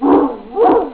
Dog 2
DOG_2.wav